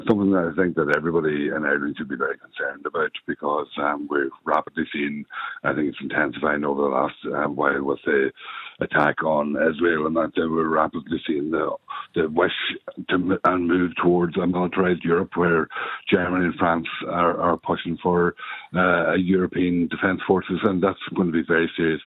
Independent TD Thomas Pringle believes there has been an intensification in efforts to militarise the bloc………..